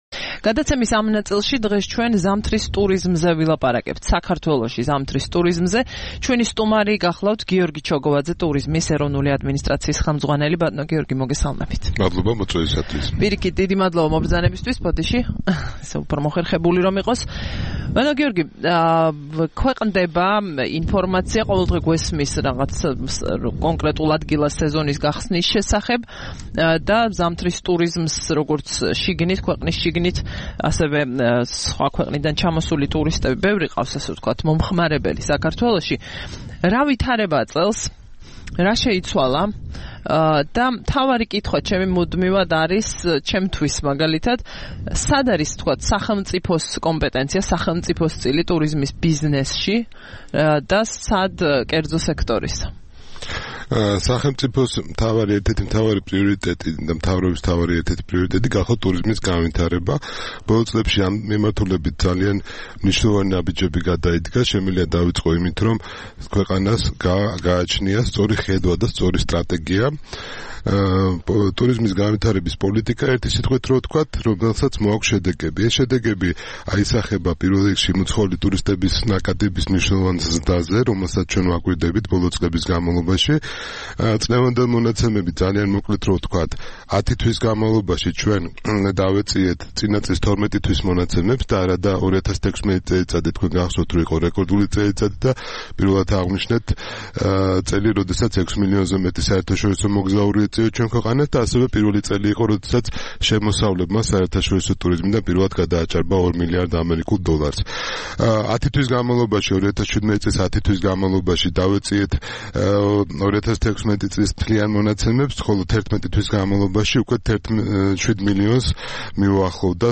15 დეკემბერს რადიო თავისუფლების "დილის საუბრების" სტუმარი იყო გიორგი ჩოგოვაძე, ტურიზმის ეროვნული ადმინისტრაციის ხელმძღვანელი.